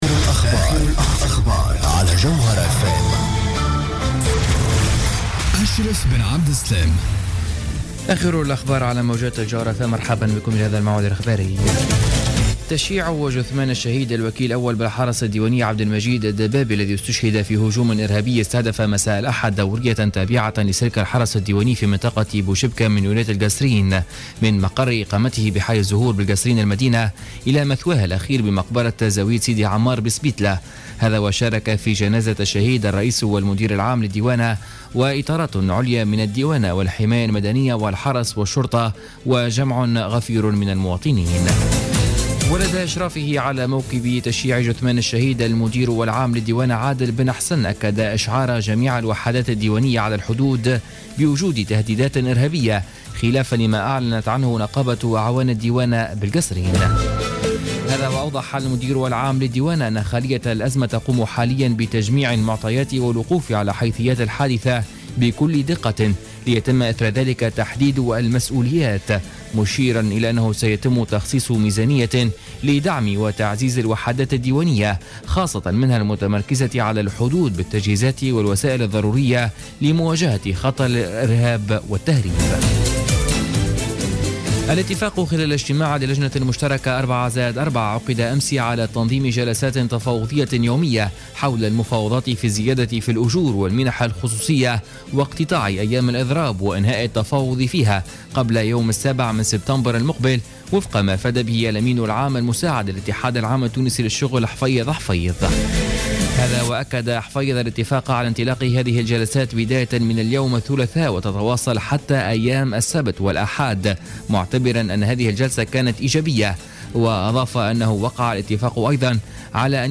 نشرة أخبار منتصف الليل ليوم الثلاثاء 25 أوت 2015